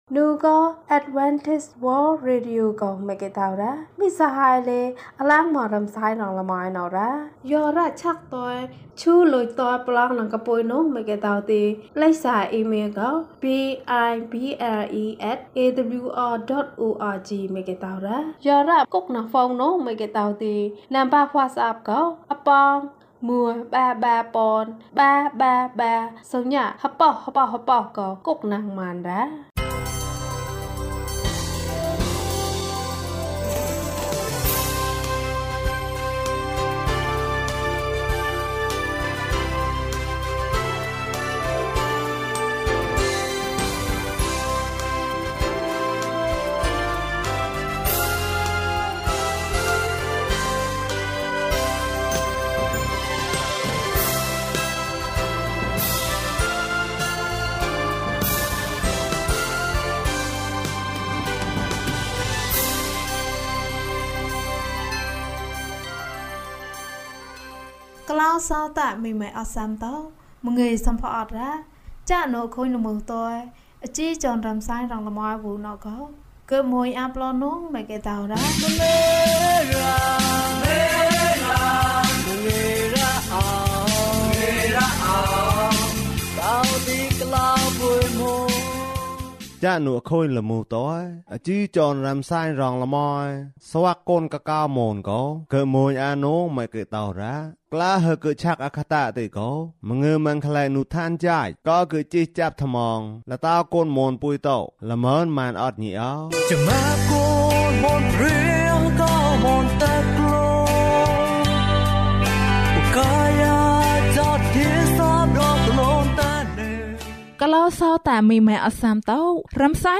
ဘုရားဟောသံကြားတယ်။ ကျန်းမာခြင်းအကြောင်းအရာ။ ဓမ္မသီချင်း။ တရားဒေသနာ။